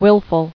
[wil·ful]